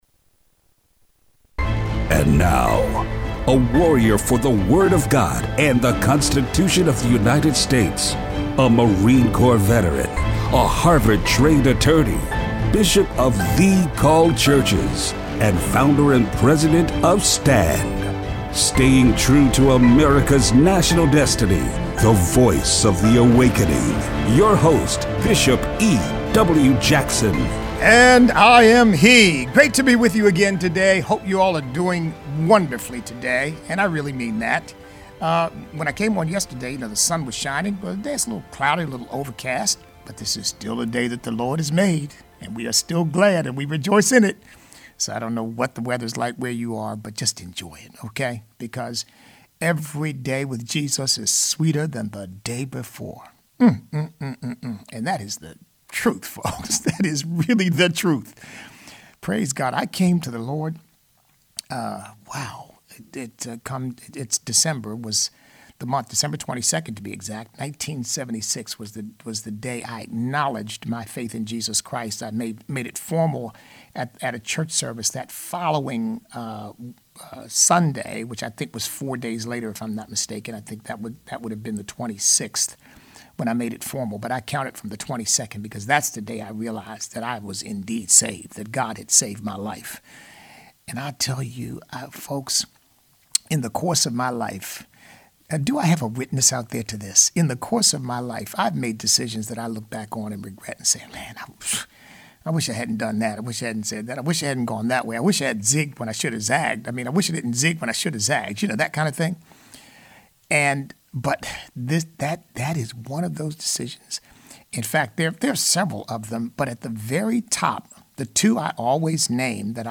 Show Notes A.G. Barr warns other politicians of discriminating against Christians. Georgia Democrat Vernon Jones forced to resign after endorsing Trump. Listener call-in.